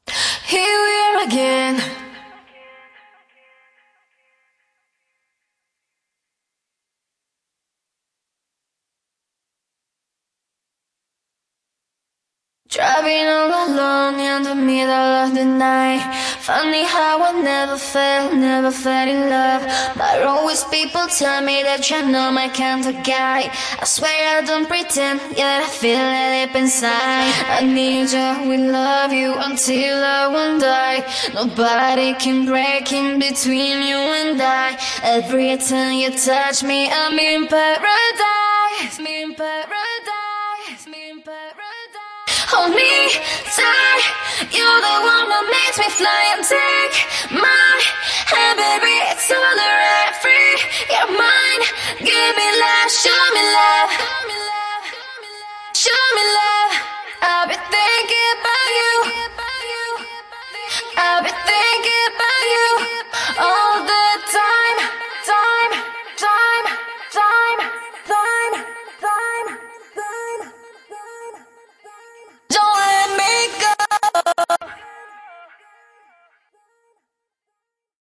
Acappella